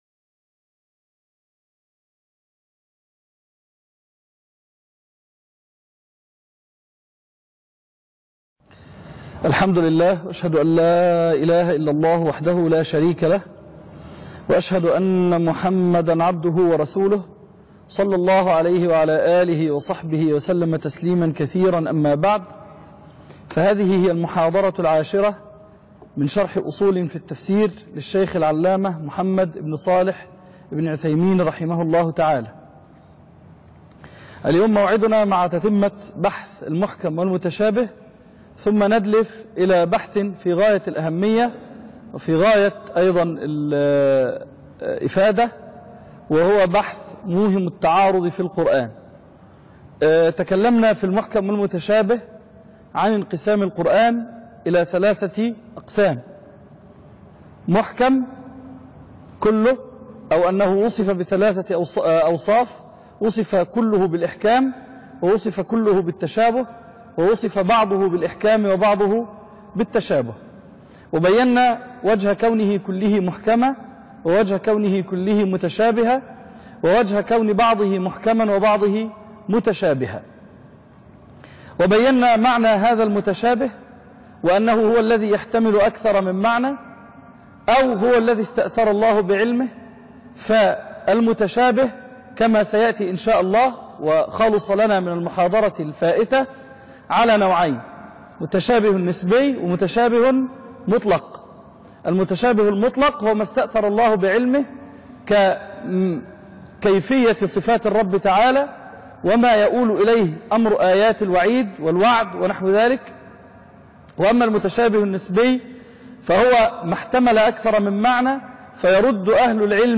( 10) موهم التعارض - شرح كتاب أصول في التفسير